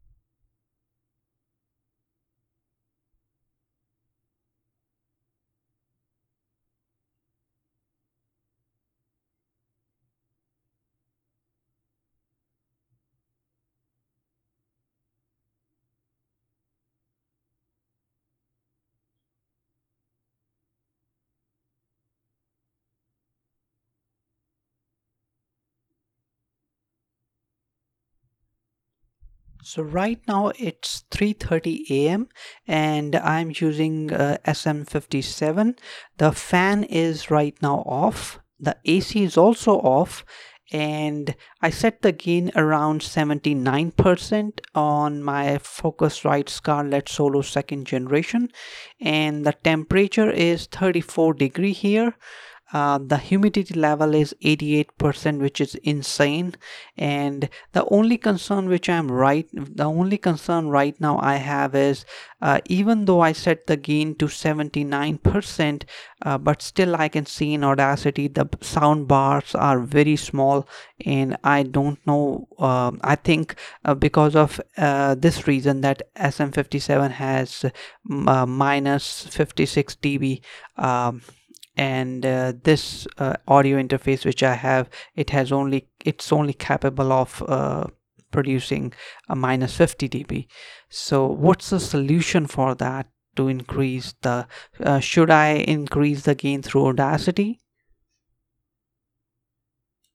Then I taken the fresh file of SM57 and again Normalize it at -0db and then use Noise reduction effect but still it's waveform don't look similar to your file:
View attachment 79% gain_SM57__-0db_Normalize.mp3